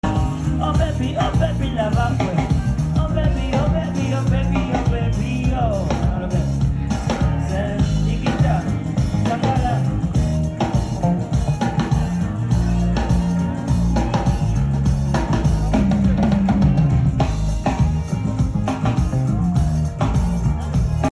concert sound check